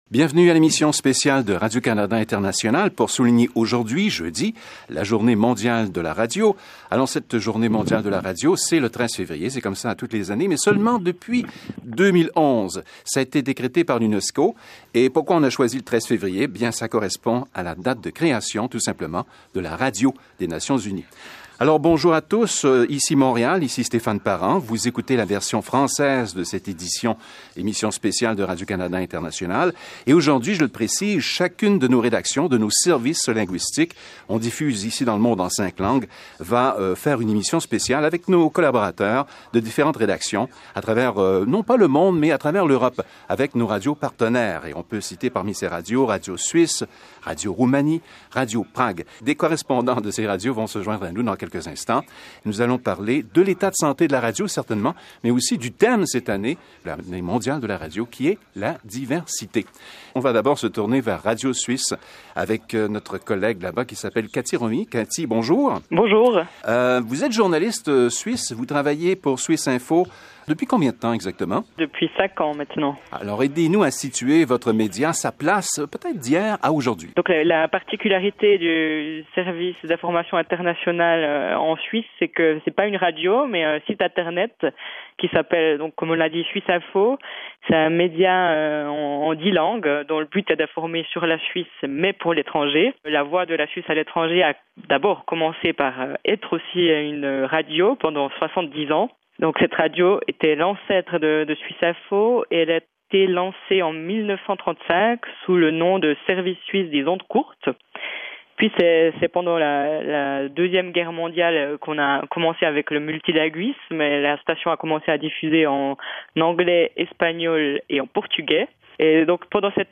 C’est ainsi que pour la première fois, dans cette émission de 20 minutes, Radio Canada International réunit trois partenaires internationaux pour vous faire découvrir leurs diversités.